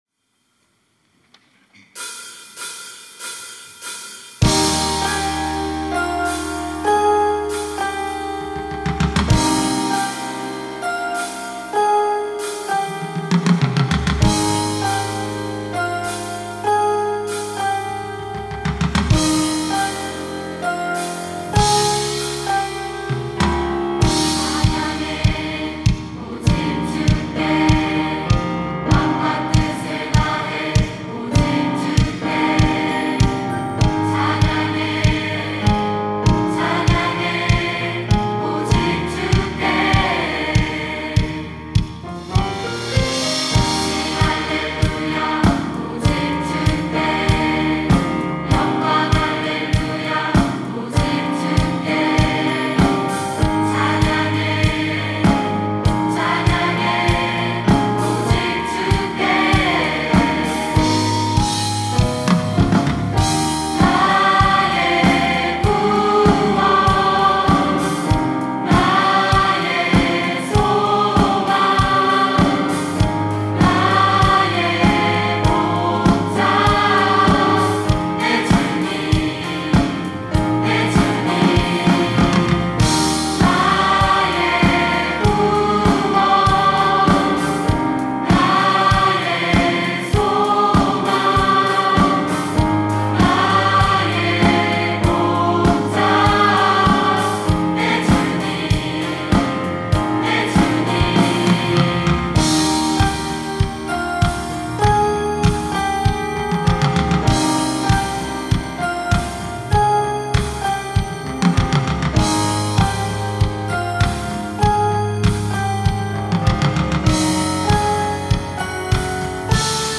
특송과 특주 - Every Praise
청년부 1청년회 연합